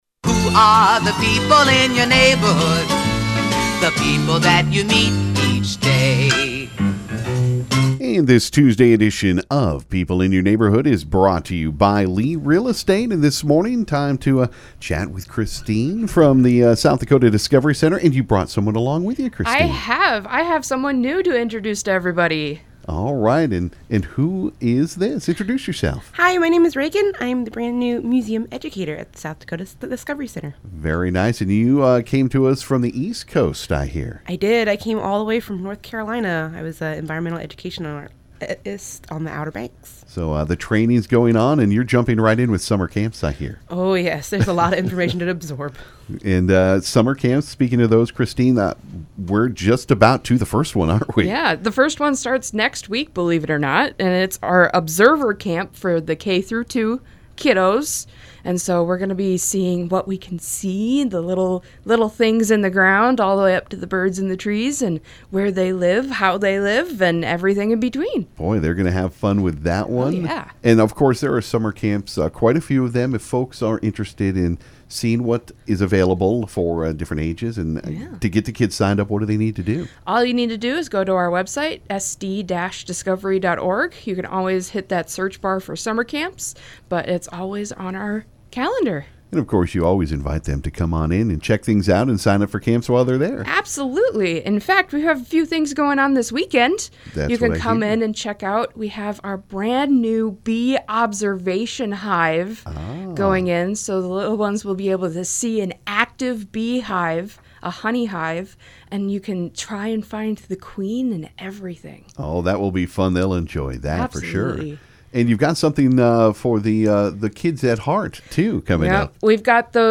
This morning on People In Your Neighborhood we had guests from the South Dakota Discovery Center on KGFX.